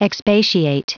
Prononciation du mot expatiate en anglais (fichier audio)
Prononciation du mot : expatiate